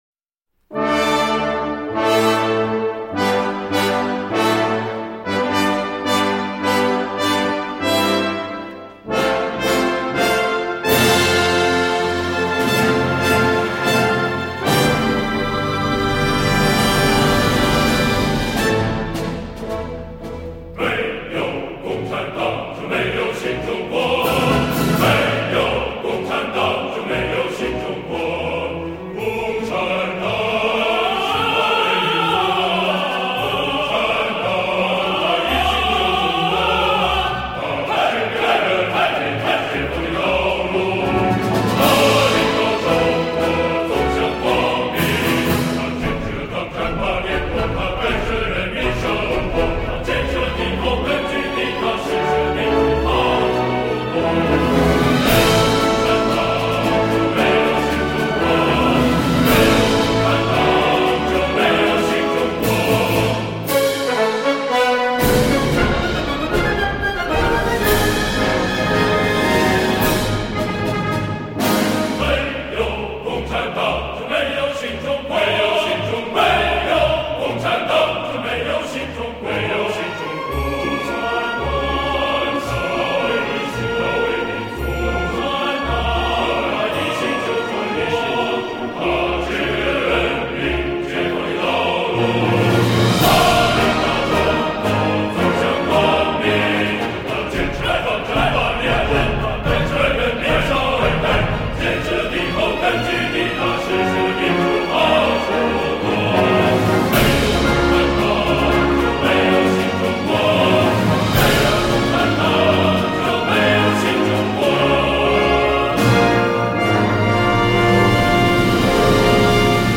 紅歌——沒有共産黨就(jiù)沒有新中國.mp3